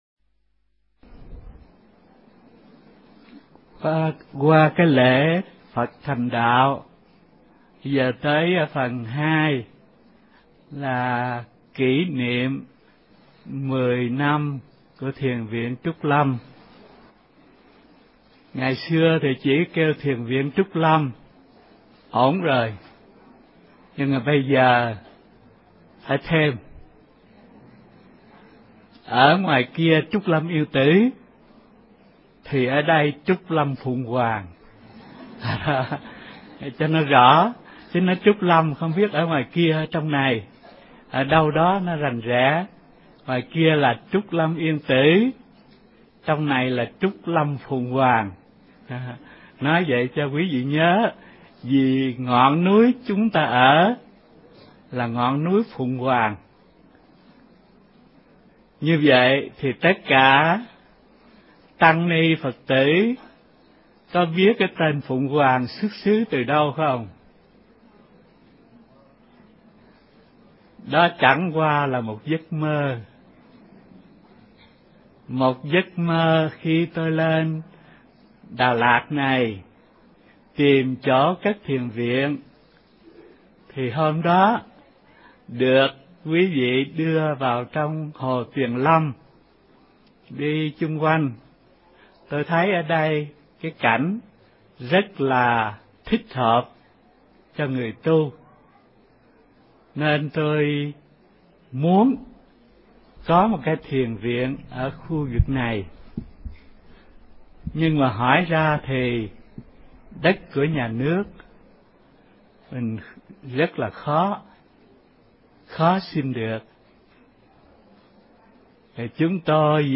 Mp3 Pháp Âm Lễ Phật Thành Đạo Năm 2003 – Hòa Thượng Thích Thanh Từ Thuyết Pháp